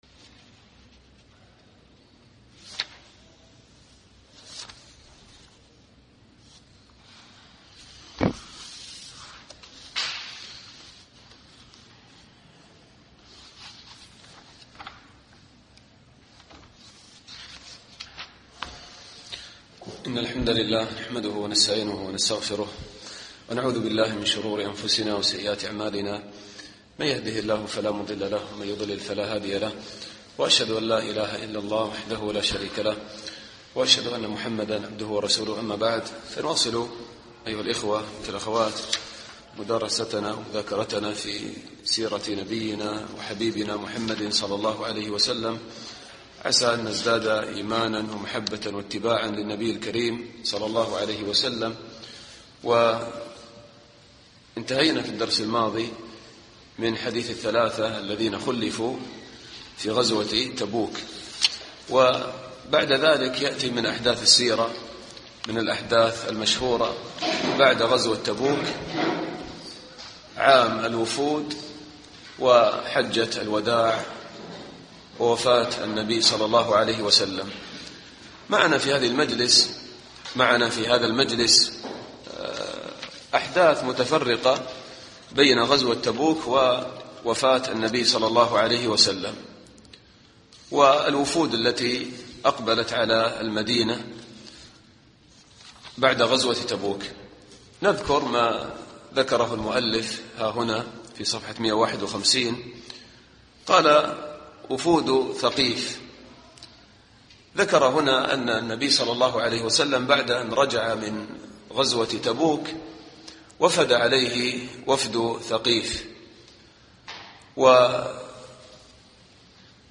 الدرس الحادي والعشرون